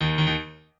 piano4_39.ogg